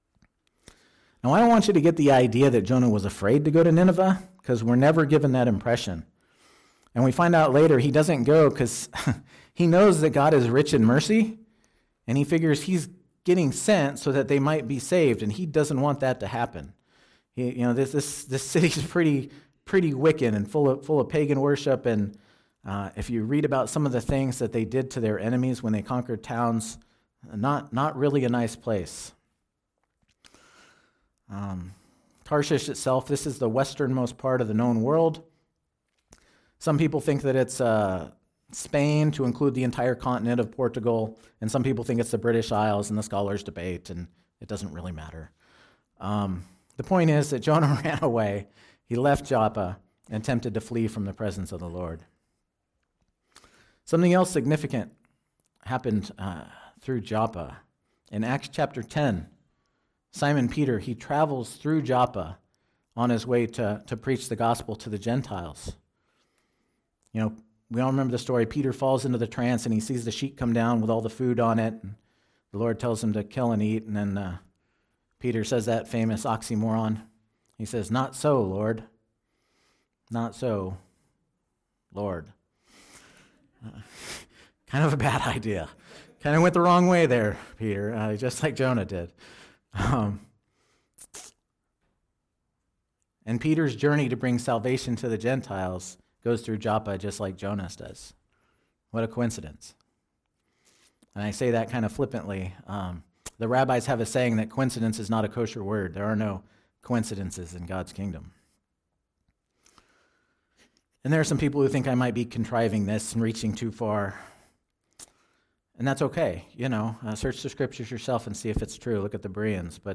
**Some of this week's sermon audio was unfortunately lost.